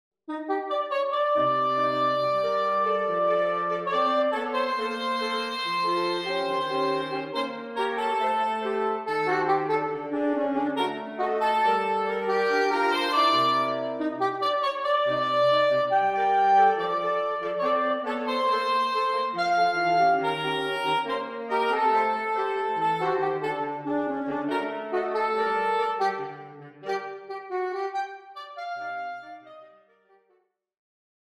(Gentle bossa nova)
Soprano Saxophone
Alto Saxophone
Tenor Saxophone
Baritone Saxophone